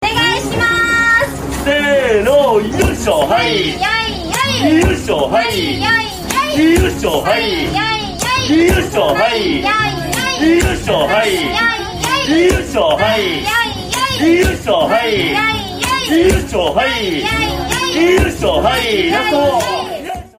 Thể loại: Câu nói Viral Việt Nam
Hiệu ứng âm thanh này có tiết tấu nhanh, giai điệu vui tươi với những câu lặp như “Hay Yay Yay Yoshi” hay “Ay Ay Ay Yoshi”, gợi cảm giác đáng yêu, ngộ nghĩnh.